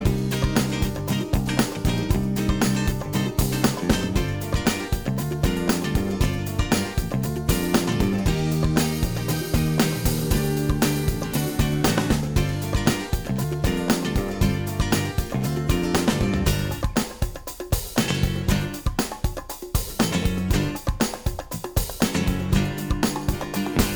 Minus All Guitars Pop (1970s) 3:25 Buy £1.50